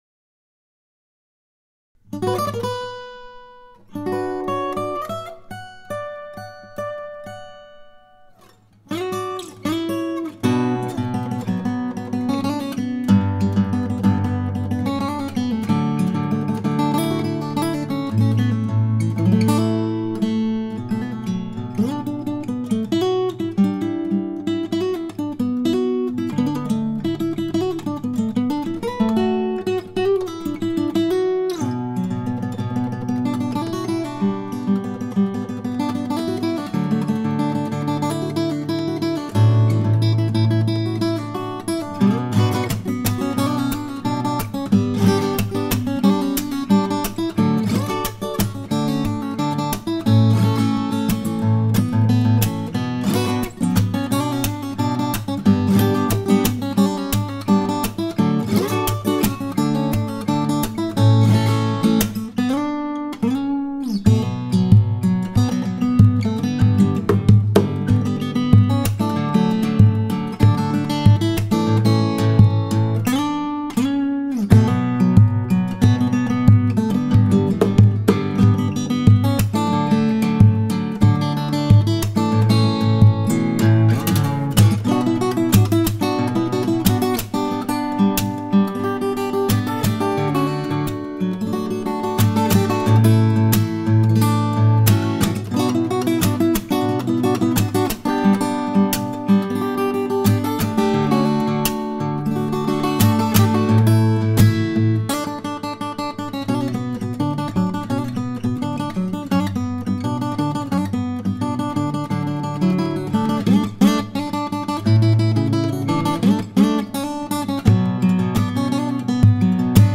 جلوه های صوتی
دانلود صدای گیتار 4 از ساعد نیوز با لینک مستقیم و کیفیت بالا